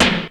GVD_snr (1).wav